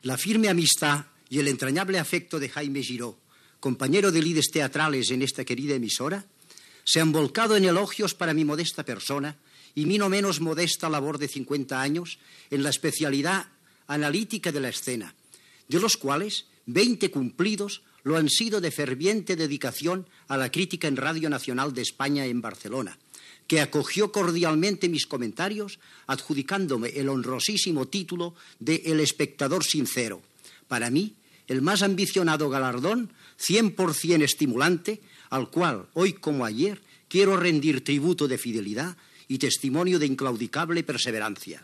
Cultura
Fragment procedent del programa "La ràdio a Catalunya" (1986) de Ràdio 4.